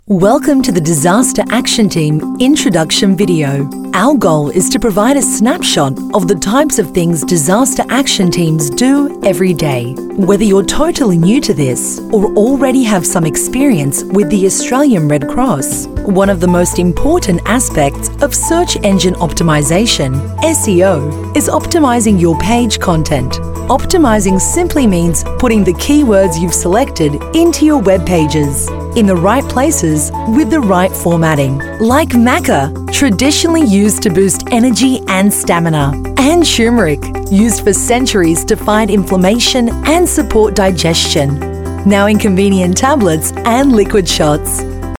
Female
Corporate
Corporate, E-Learning